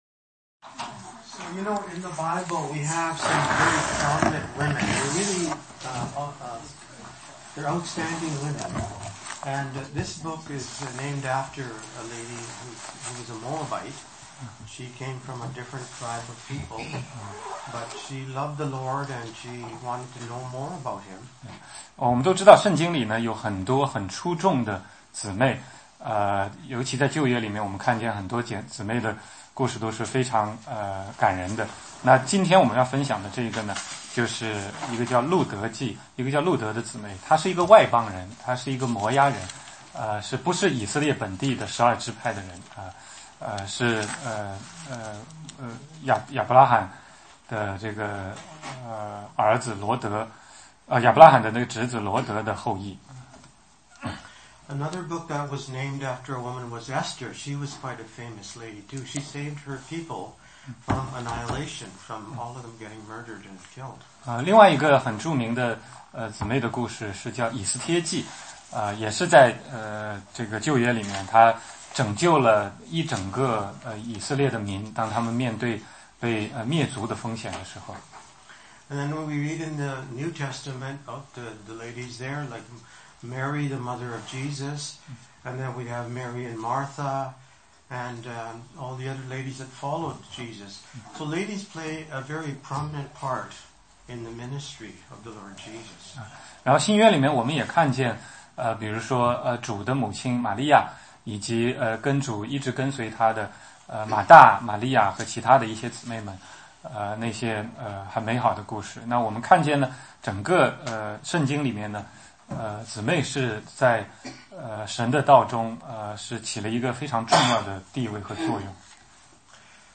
16街讲道录音 - 路德记1,2